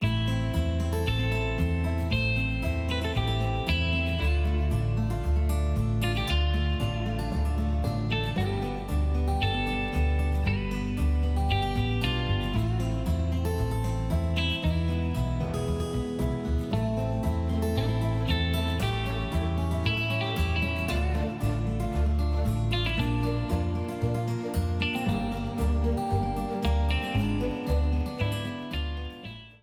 A blue streamer theme
Ripped from the game
clipped to 30 seconds and applied fade-out